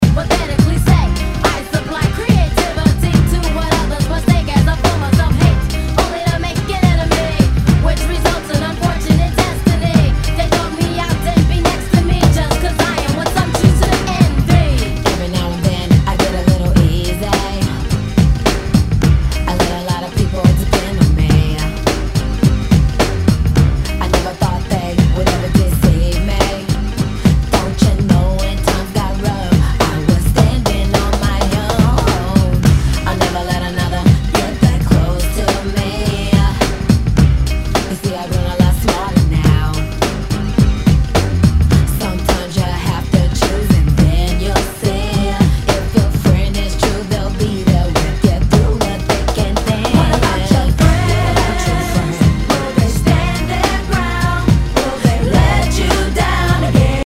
HIPHOP/R&B
[VG ] 平均的中古盤。スレ、キズ少々あり（ストレスに感じない程度のノイズが入ることも有り）